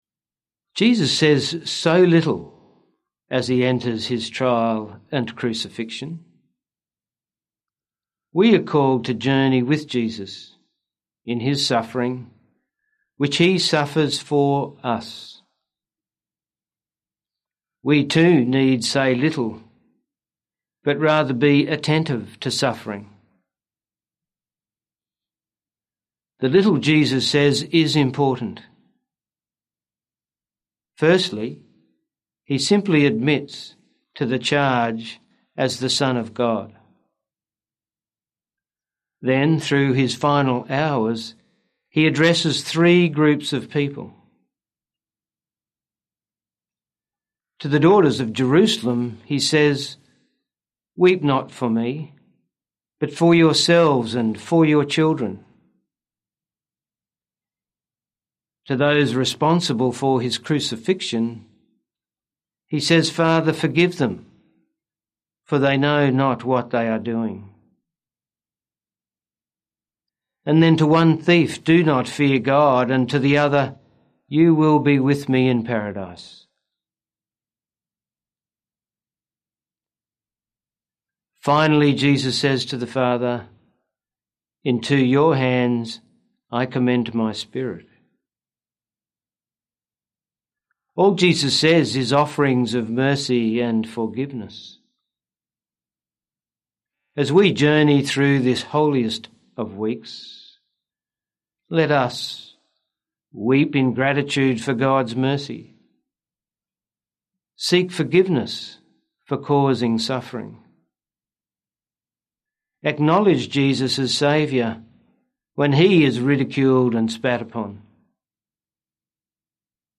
Archdiocese of Brisbane Palm Sunday - Two-Minute Homily